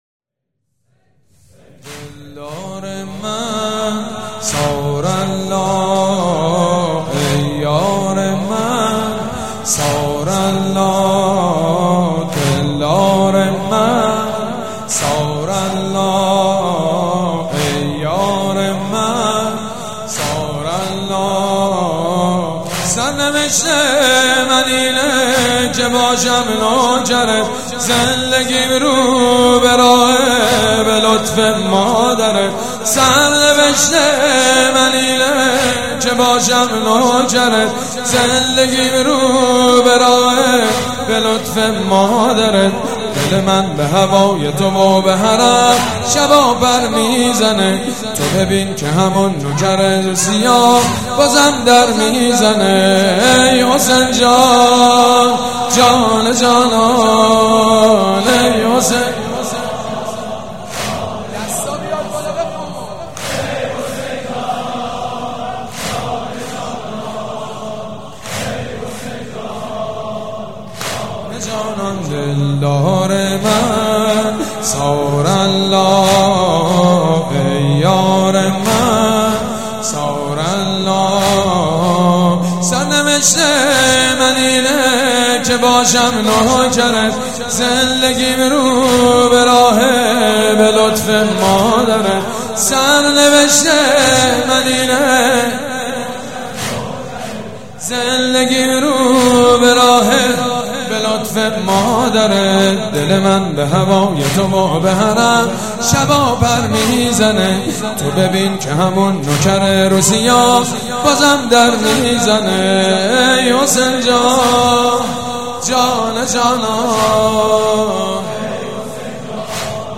دسته بندی :تواشیح , صوت , مدیحه سرایی , مذهبی , مرثیه سرایی
دانلود مداحی و روضه خوانی شب هشتم ماه محرم در سال 1396